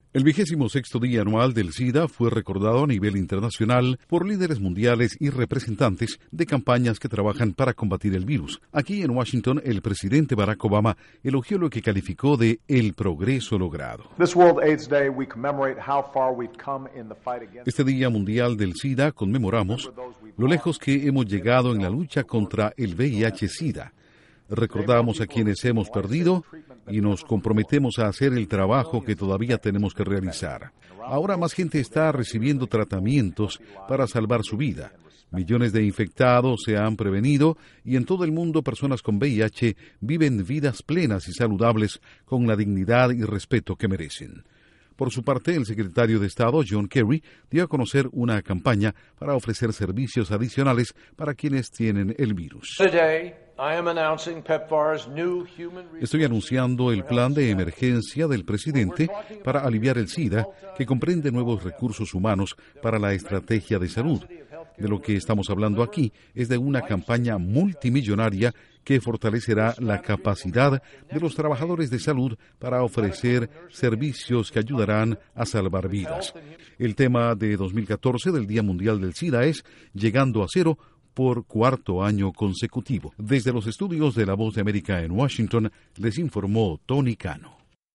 Obama elogia los avances para combatir el SIDA, y anuncia una iniciativa multimillonaria. Informa desde los estudios de la Voz de América en Washington